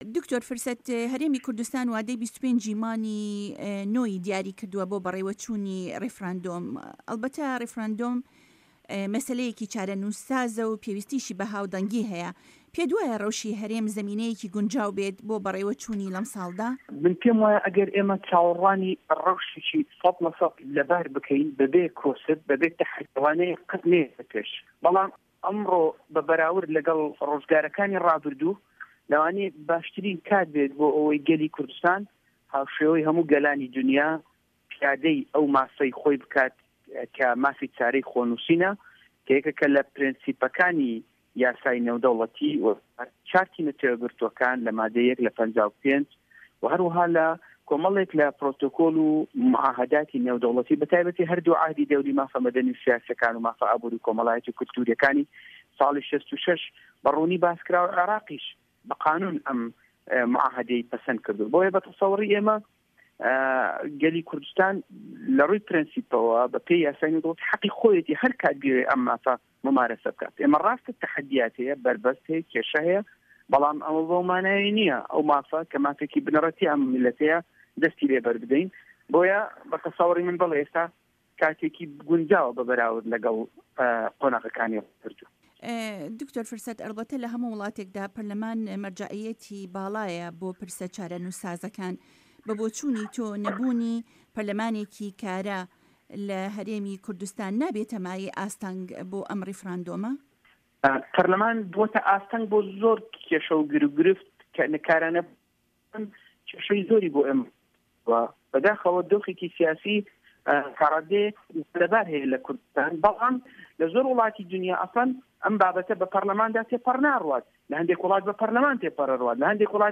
د. فرسەت سۆفی ئەندامی پەرلەمانی هەرێمی کوردستان لە گفتوگۆییەکی تایبەتدا لەگەڵ دەنگی ئەمەریکادا ڕایگەیاند " ڕاستە تەحەدیات و بەربەست و کێشە هەن بەڵام ئەوە مافێکی بنەڕەتی ئەم میللەتەیە و دەستی لێهەڵناگرێن."